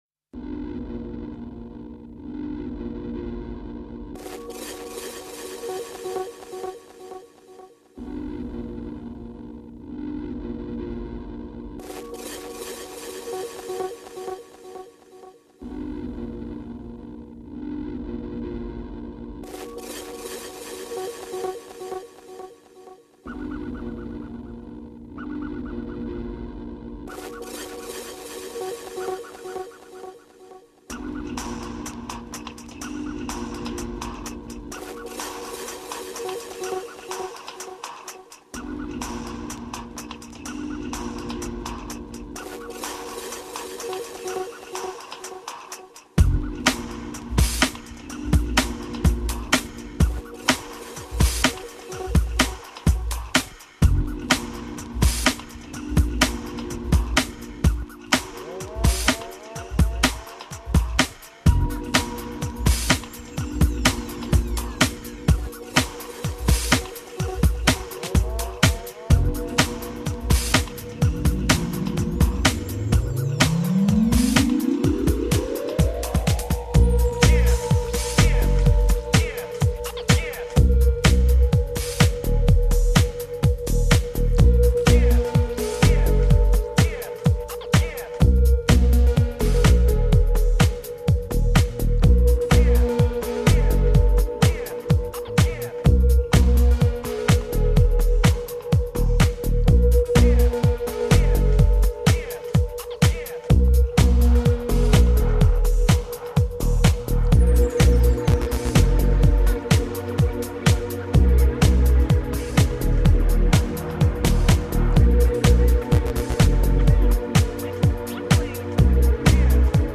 (mono)